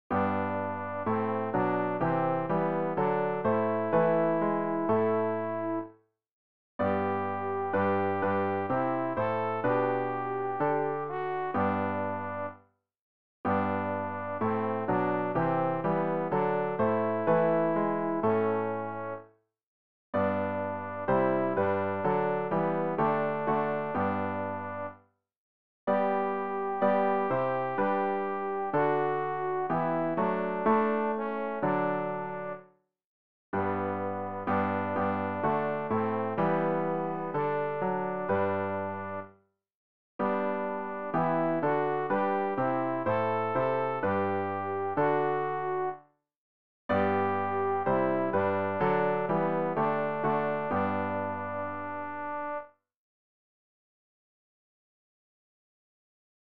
Übehilfen für das Erlernen von Liedern
alt-rg-055-singt-singt-dem-herren-neue-lieder.mp3